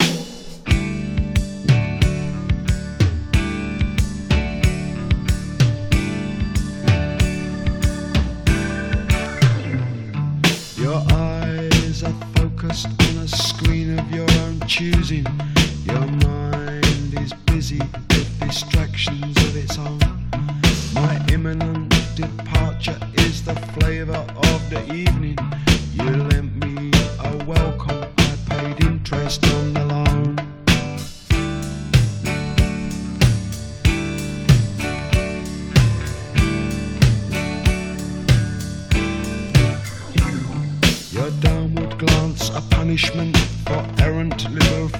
バハマ、コンパス・ポイント・スタジオ録音。
南国感有り、気怠い男たちな印象有り、そしてちょっぴり哀愁。
Rock, Electronic, Reggae　France　12inchレコード　33rpm　Stereo